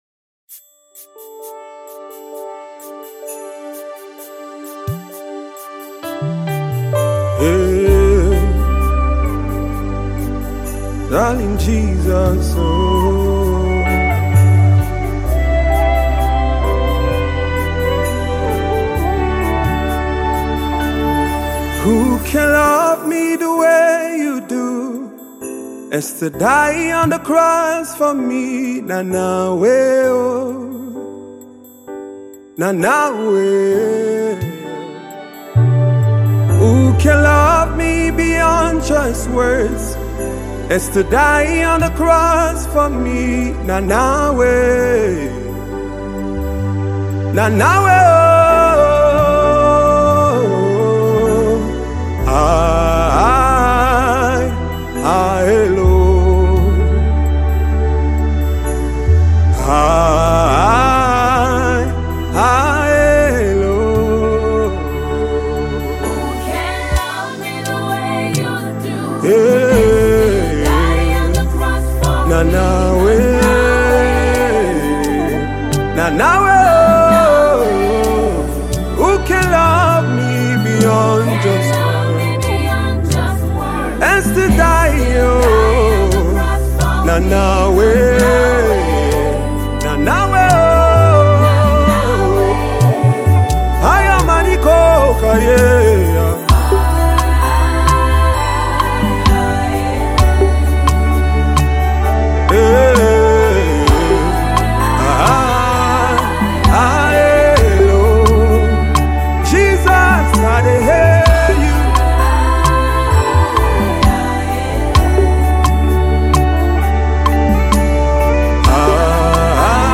Nigerian Gospel Music
versatile gospel singer